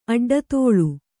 ♪ aḍḍatōḷu